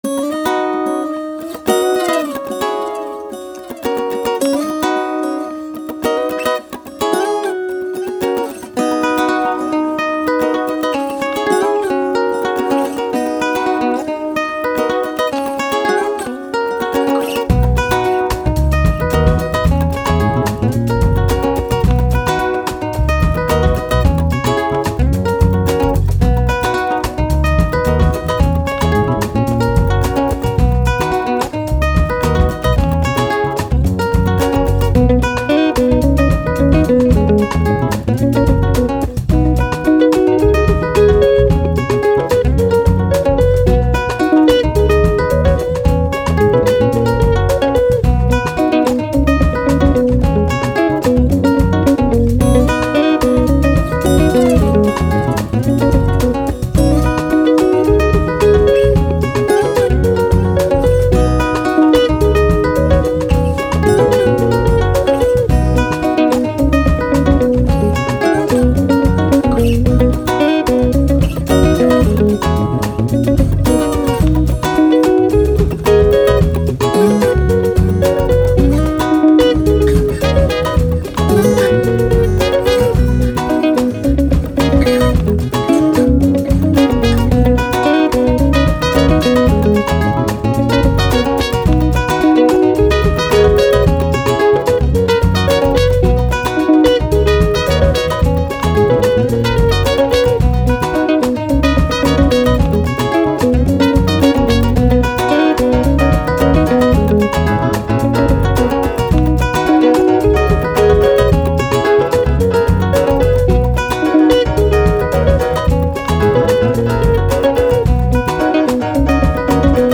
Afrobeat, Chilled, Playful, Relaxed, Fun, Positive, Sun